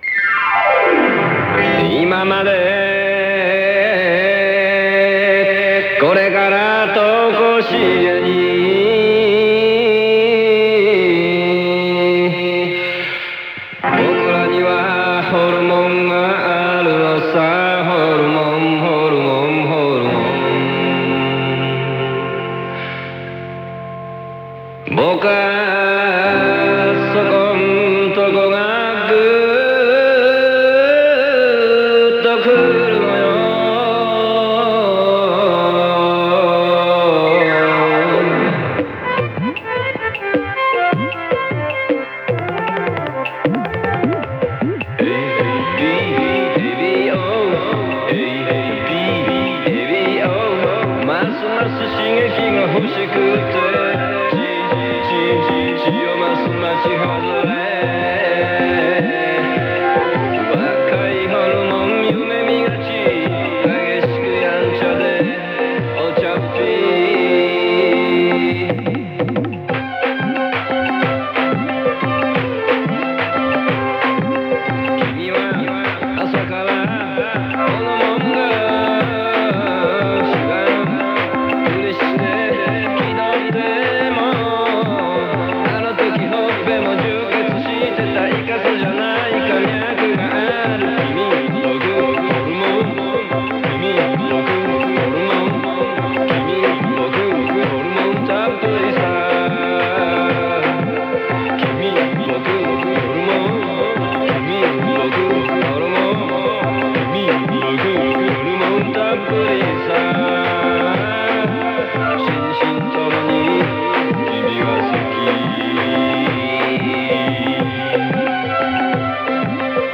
ラジオエアチェック